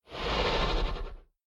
Minecraft Version Minecraft Version latest Latest Release | Latest Snapshot latest / assets / minecraft / sounds / mob / horse / zombie / idle2.ogg Compare With Compare With Latest Release | Latest Snapshot